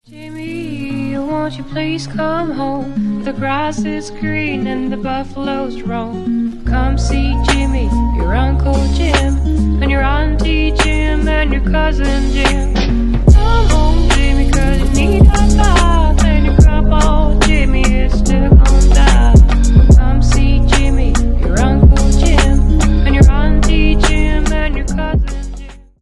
бесплатный рингтон в виде самого яркого фрагмента из песни
Поп Музыка
спокойные